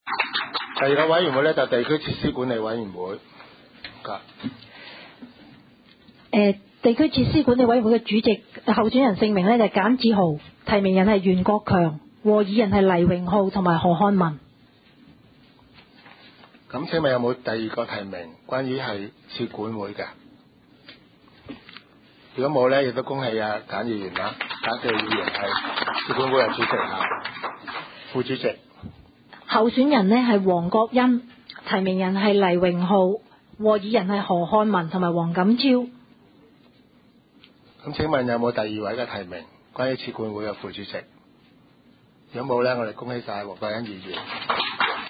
委员会会议的录音记录
地区设施管理委员会第一次会议 日期: 2012-01-17 (星期二) 时间: 下午2时30分 地点: 九龙黄大仙龙翔道138号 龙翔办公大楼6楼 黄大仙区议会会议室 议程 讨论时间 I 选举委员会主席及副主席 0:00:45 全部展开 全部收回 议程:I 选举委员会主席及副主席 讨论时间: 0:00:45 前一页 返回页首 如欲参阅以上文件所载档案较大的附件或受版权保护的附件，请向 区议会秘书处 或有关版权持有人（按情况）查询。